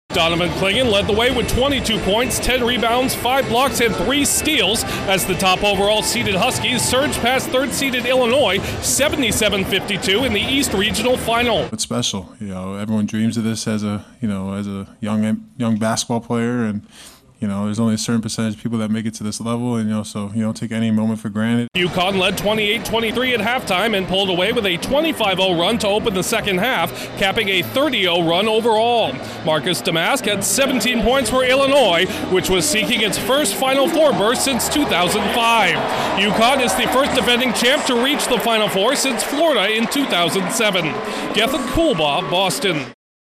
UConn is two wins away from a second straight NCAA title. Correspondent